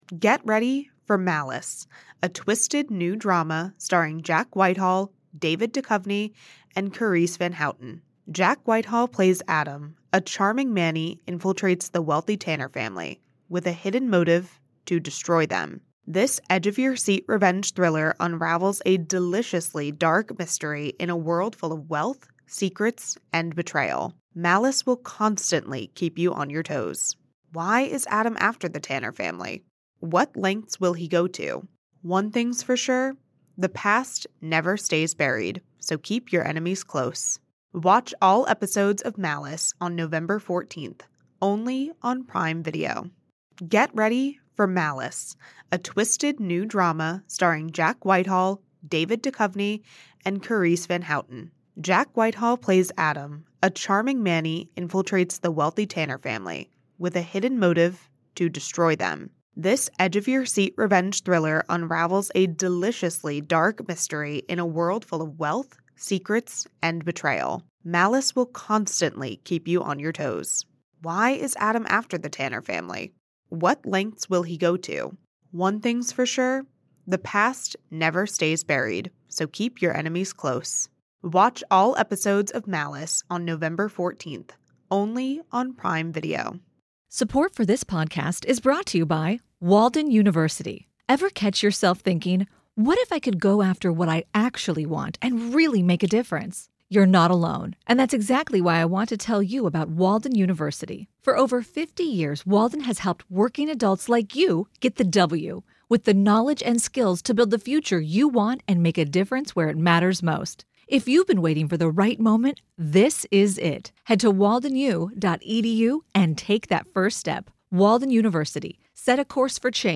We discuss how 2021 unfolded for all of us, what we see coming on the horizon, and where the trucking industry is possibly headed. We also play a long line of voicemails, which have been piling up for a quite while!